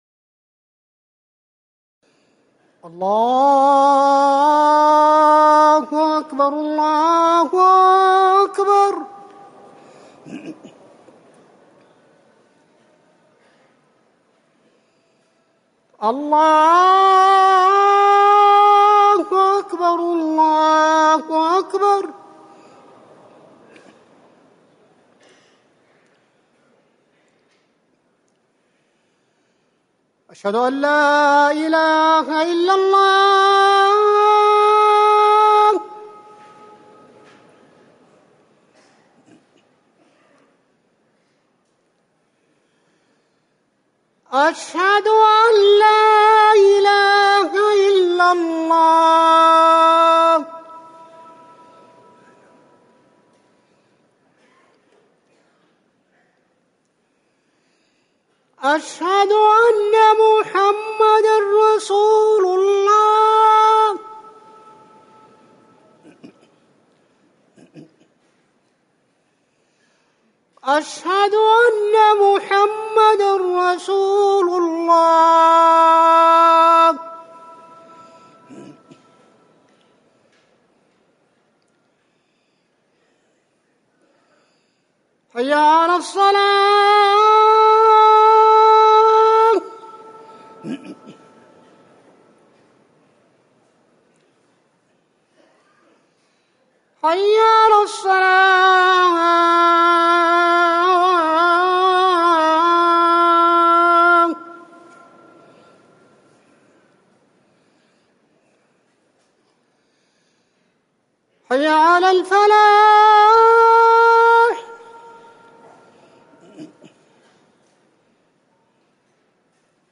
أذان العشاء
تاريخ النشر ٢٦ محرم ١٤٤١ هـ المكان: المسجد النبوي الشيخ